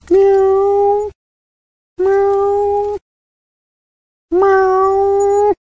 描述：2只猫有争执
标签： 愤怒 参数 叫声
声道立体声